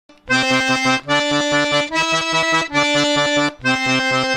Lesson #11 - Bounces
I've heard it called: "bounces, staccato, woodpecker", etc.
bounces.wma